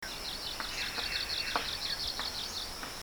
Songs
7 May 2012 Tin Shui Wai
This song sounds a little pulsating, and some of the notes are slightly different, but still this should be a borealis.